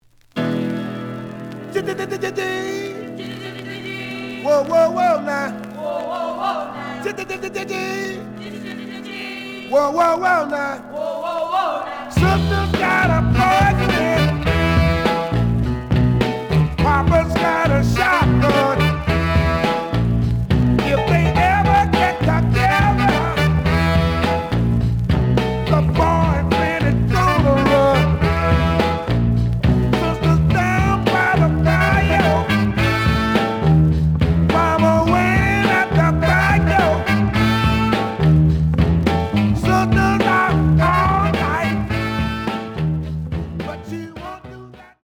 The audio sample is recorded from the actual item.
●Genre: Funk, 60's Funk
Slight edge warp.